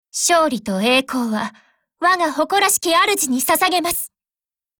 Cv-20220_warcry.mp3